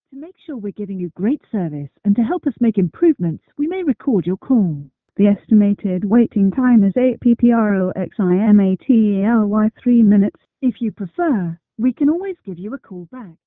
Just had this *amazing* experience while on hold to a call centre.